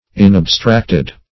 \In`ab*stract"ed\